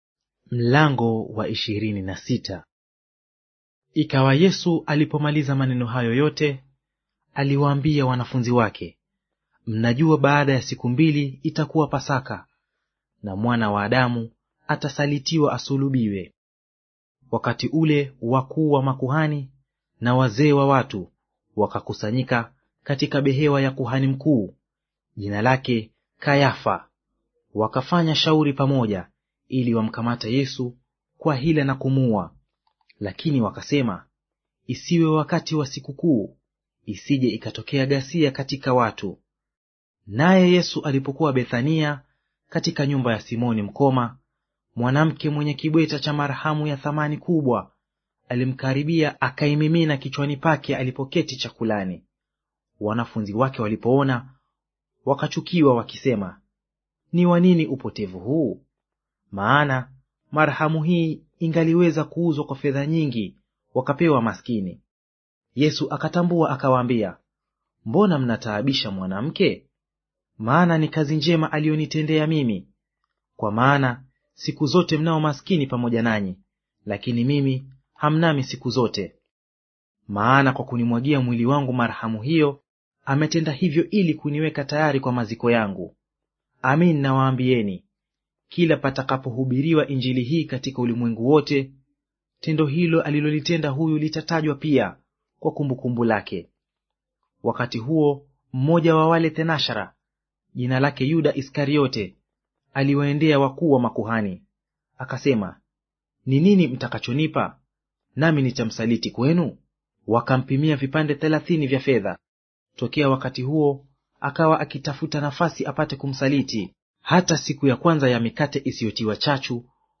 Audio reading of Mathayo Chapter 26 in Swahili